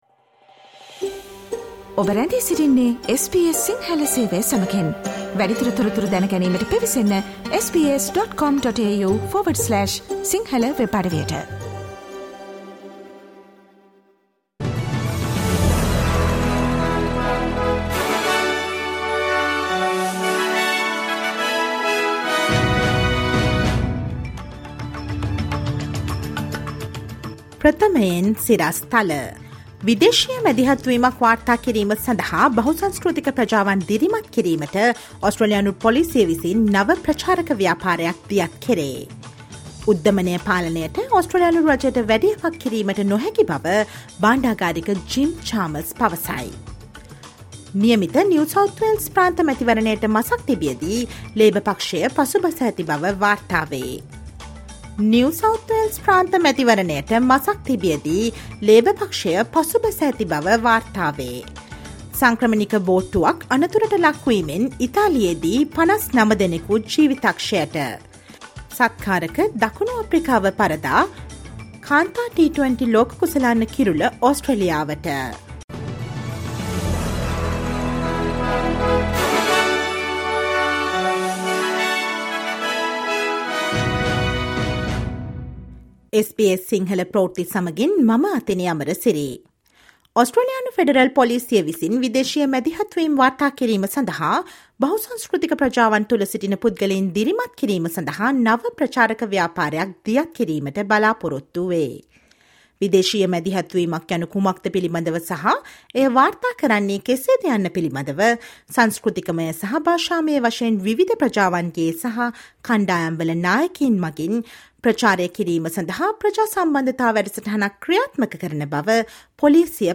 Jim Chalmers says the government isn't able to do much to contain inflation : News in Sinhala on 27 Feb